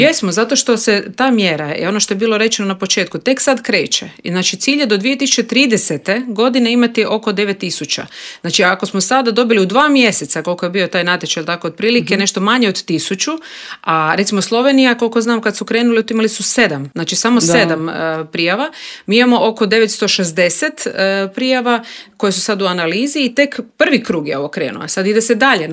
Upravo o toj temi razgovarali smo u Intervjuu Media servisa s HDZ-ovom europarlamentarkom Nikolinom Brnjac.